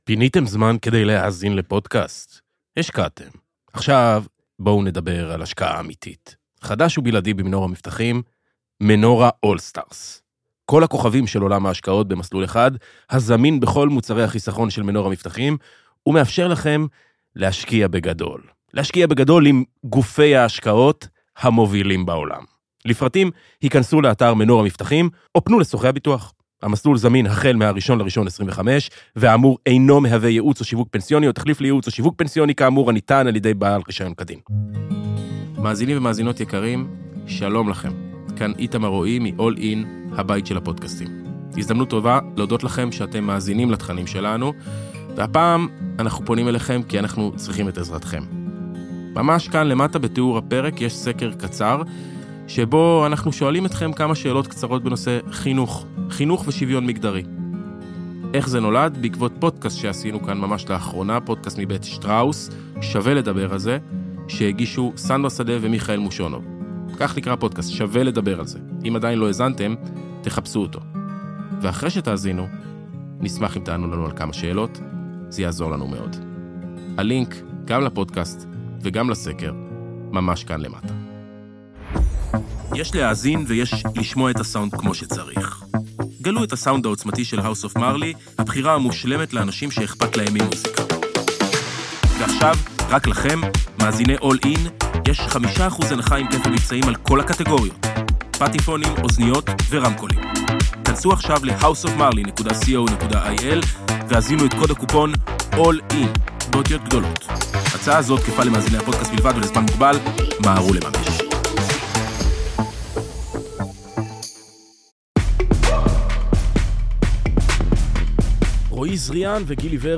ראיון עם השורדים הטריים